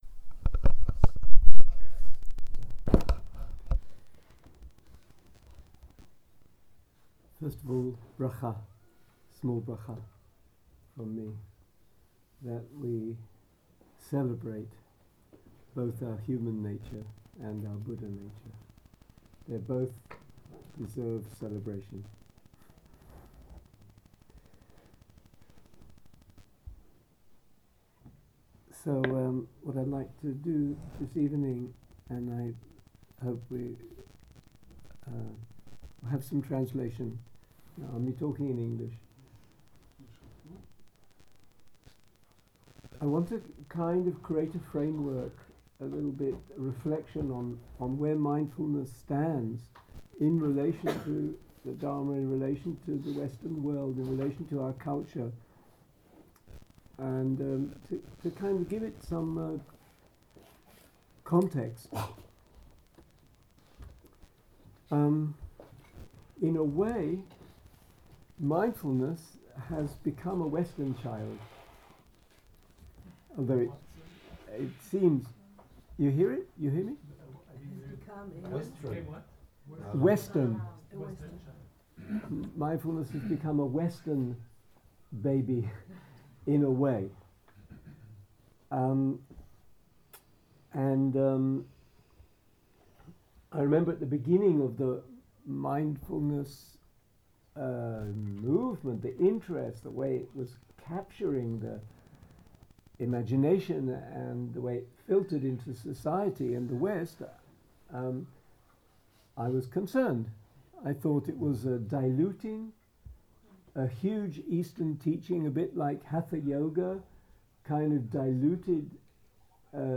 יום 1 - ערב - שיחת דהרמה - הפצת הדהרמה - הקלטה 1